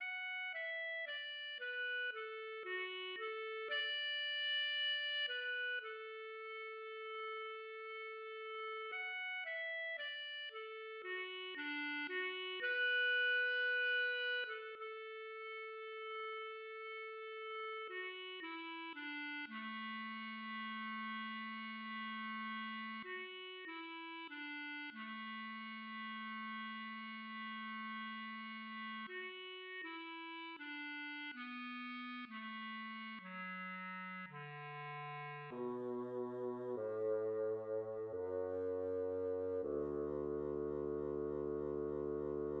KeyB minor
Replacement of a bassoon with a bass clarinet
From the anacrusis of bar 154
In the bar 160 above, the last four notes of the bassoon (shown in blue) are often played by a bass clarinet.[14][15][16]